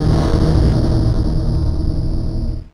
55bf-orc04-d#1.wav